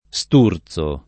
[ S t 2 r Z o ]